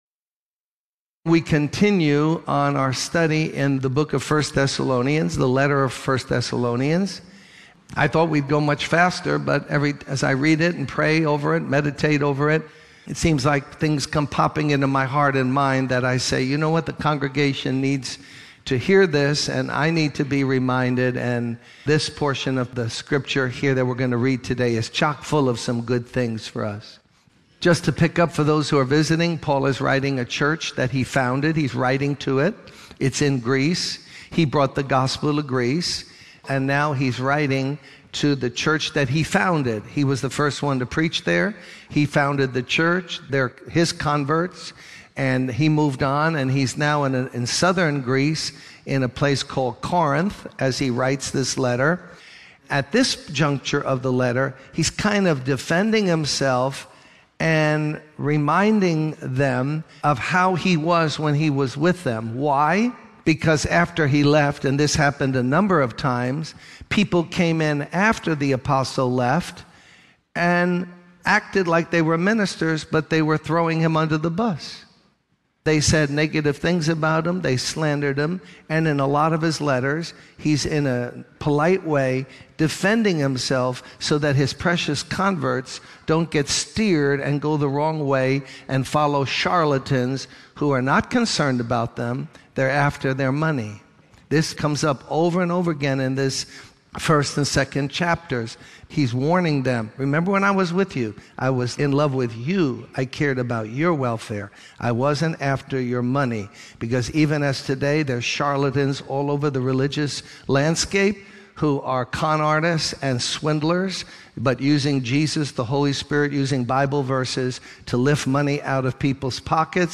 In this sermon, the preacher discusses how Satan tries to attack believers and how we can learn from this to help ourselves and others. The preacher emphasizes the importance of maturity and caring for others.